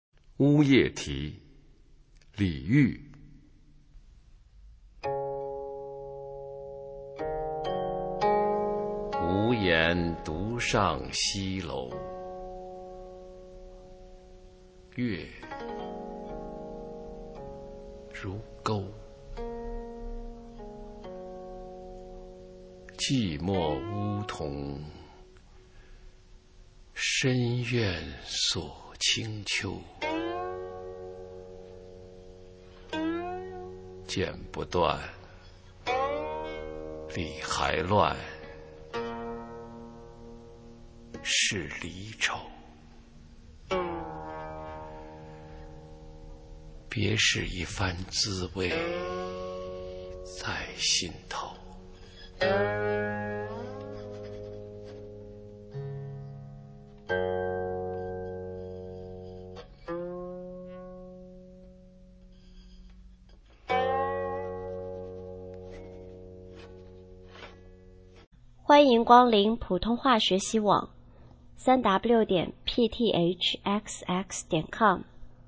普通话美声欣赏：乌夜啼